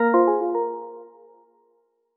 BMWi_ringtone.wav